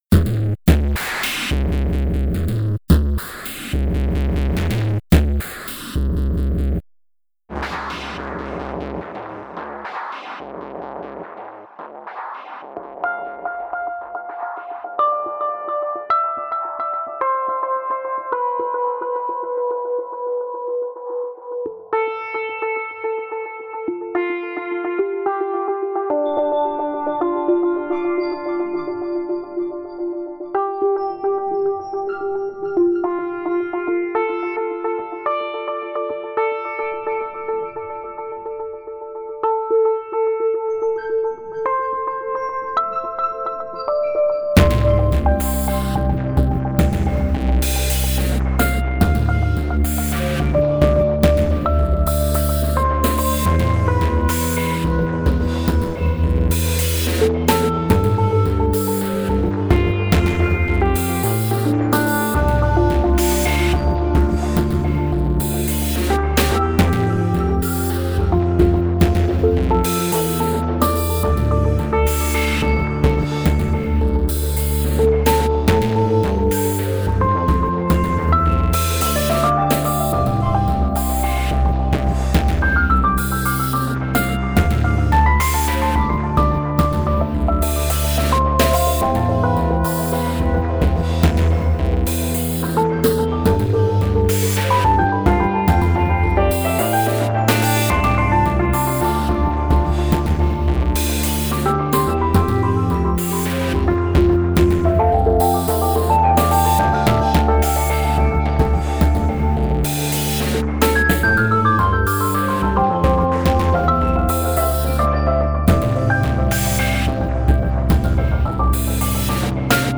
Genre: Trip-Hop, IDM.